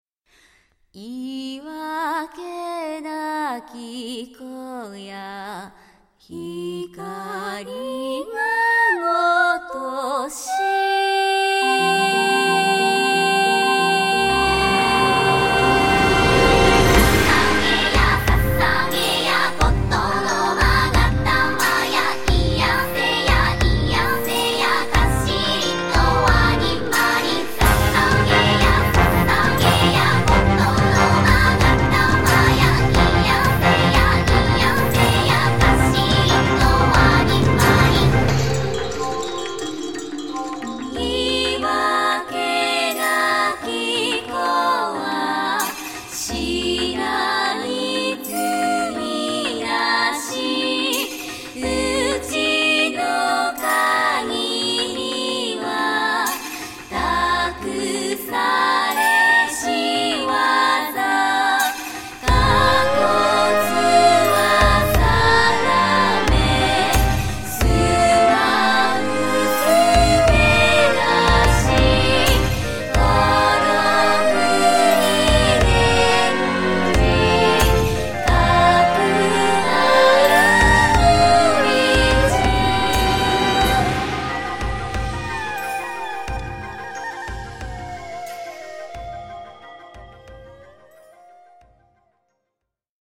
この曲は、非常にキャッチーな旋律が繰り返し出てくるため、 とても頭に残りやすい曲ですよね。
そして、メロディの響 きがとても綺麗、そして多重で壮大！と言う美味しいところだらけの楽曲になっ たのです。